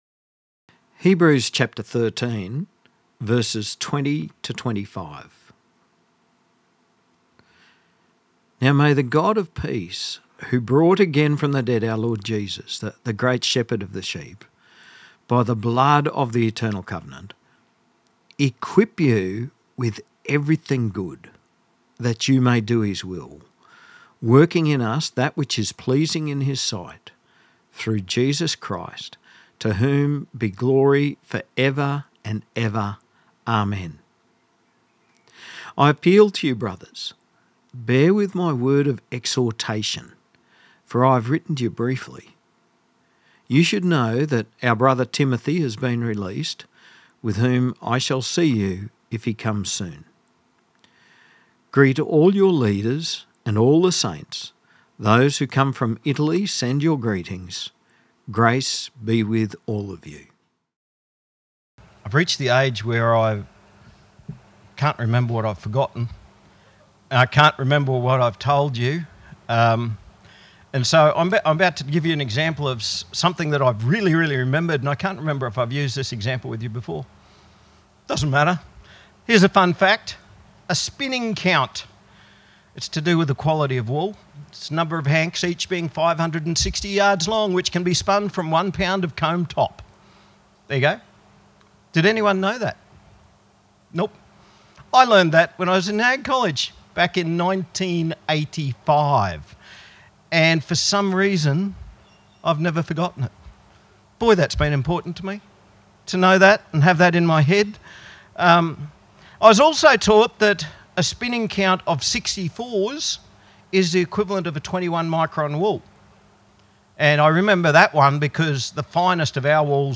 Bush Disciples Sermons
Weekly messages of the Bush Disciples evangelical Christian Church in the St George QLD district.